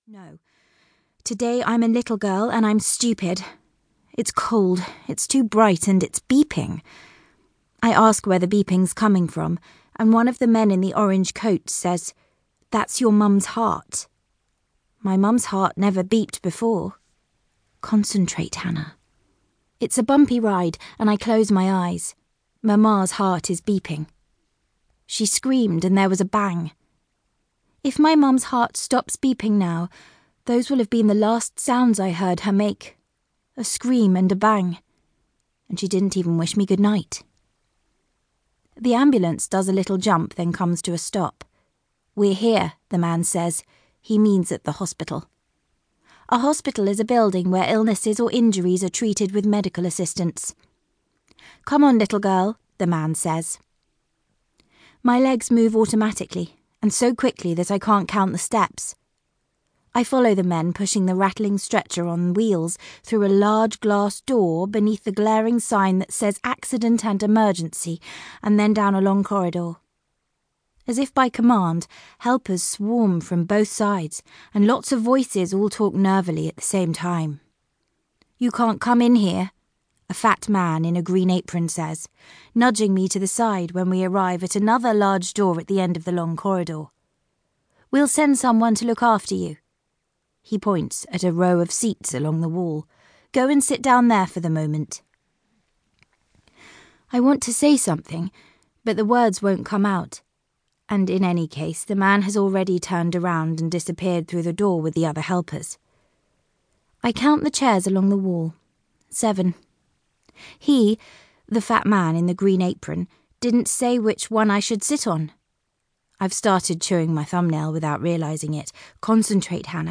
• Audiobook
"A trio of well-cast, English-accented performers embody the unreliable narrators of German author Hausmann's debut thriller, each of whom is harboring secrets...Nothing is as it seems here, and listeners will be enthralled as each new twist is revealed." --Booklist